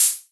• Urban Open High-Hat Sound D Key 14.wav
Royality free open hat sound tuned to the D note. Loudest frequency: 11317Hz
urban-open-high-hat-sound-d-key-14-nIx.wav